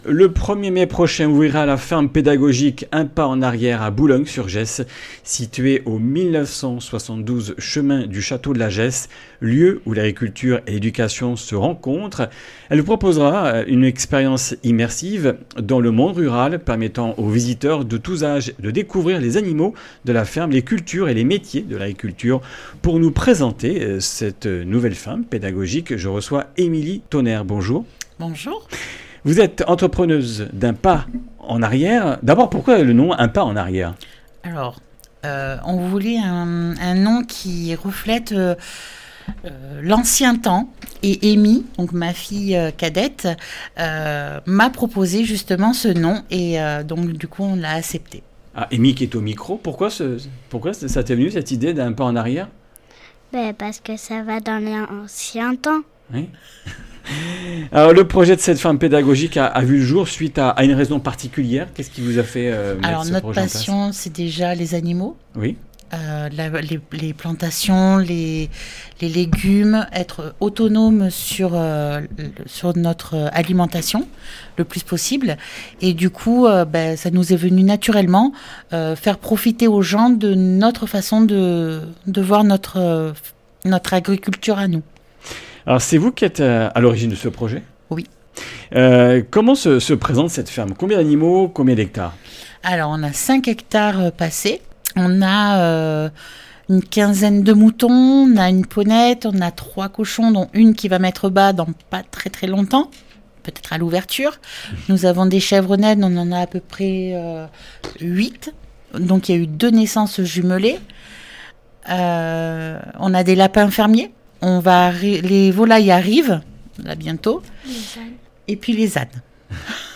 Comminges Interviews du 24 avr.
Une émission présentée par